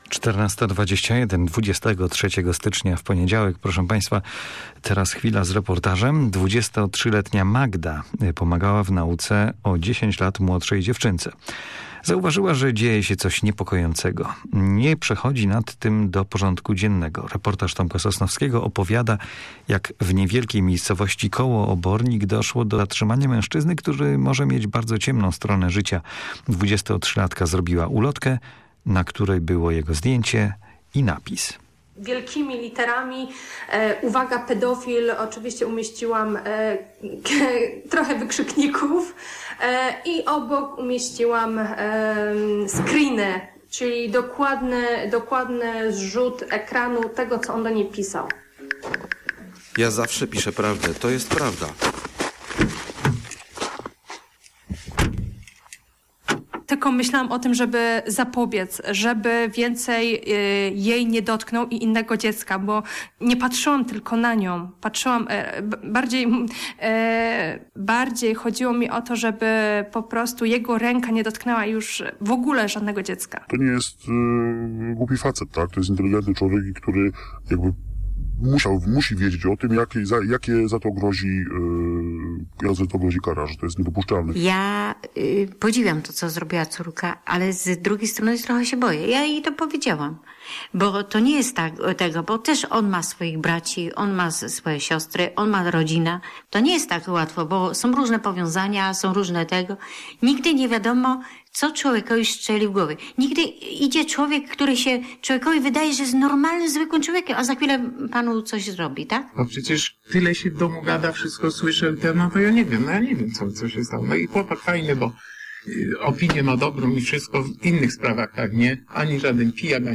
Słodka - reportaż